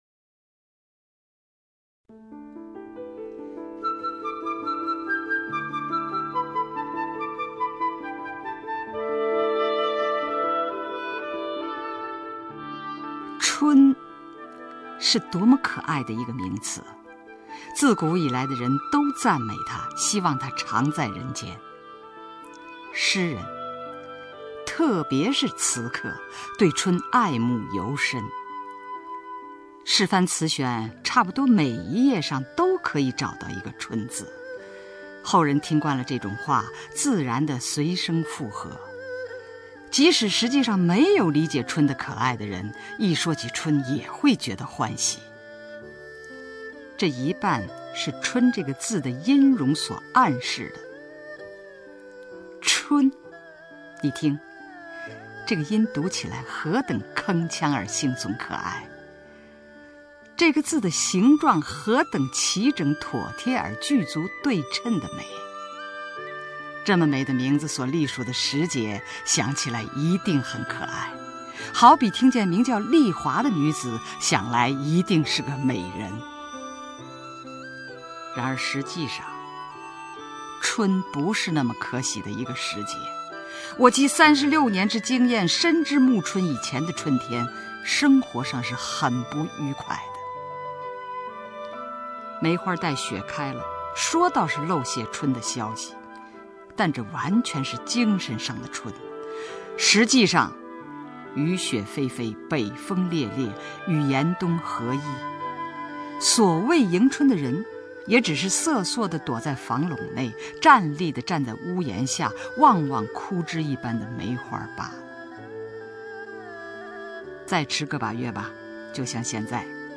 首页 视听 名家朗诵欣赏 吕中
吕中朗诵：《春（节选）》(丰子恺)